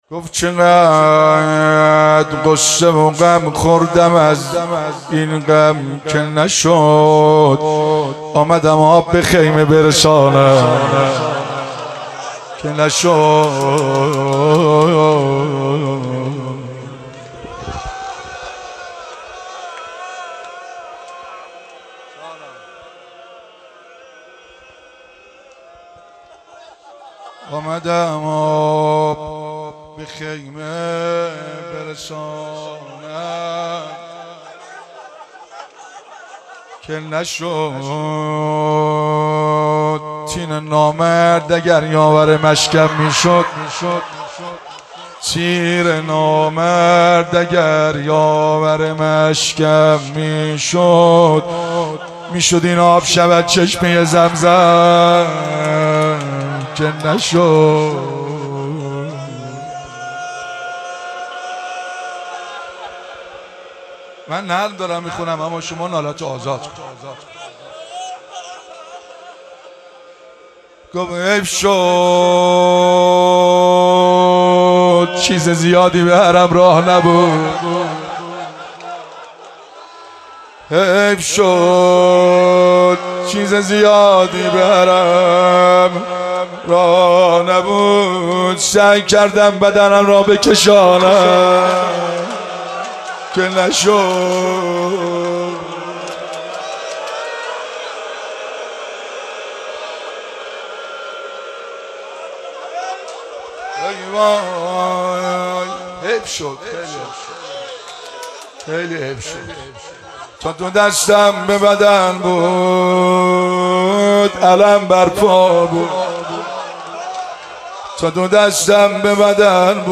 شب تاسوعا محرم 96 - هیئت فاطمیون - روضه